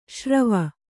♪ śrava